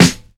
• Solid Steel Snare Drum Sound F Key 332.wav
Royality free steel snare drum tuned to the F note. Loudest frequency: 1781Hz
solid-steel-snare-drum-sound-f-key-332-tpB.wav